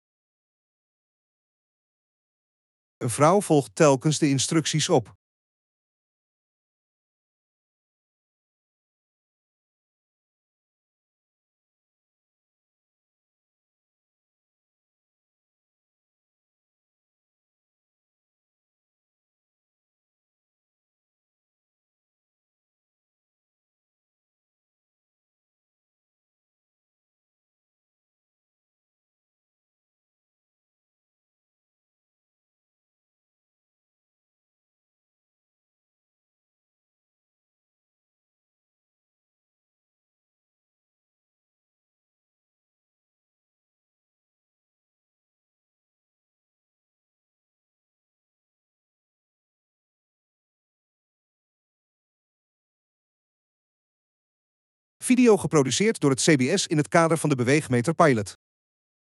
Instructievideo beweegmeter